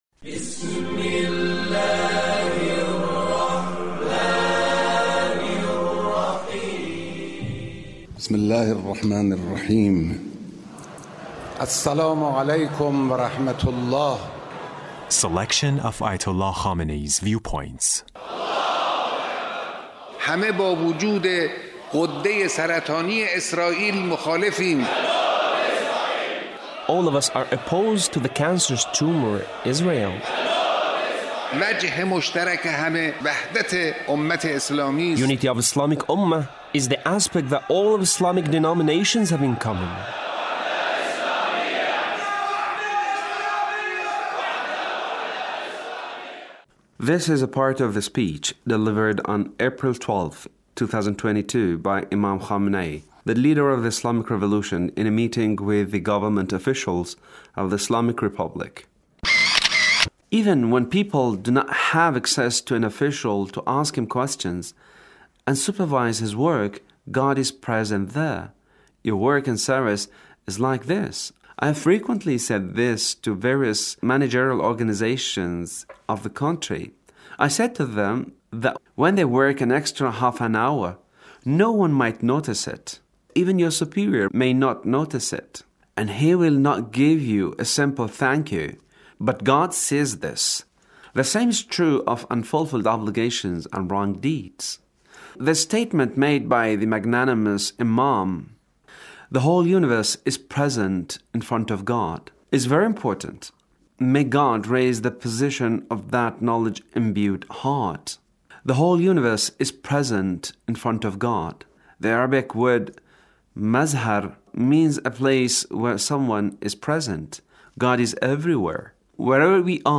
Leader's speech (1377)
The Leader's speech on Ramadan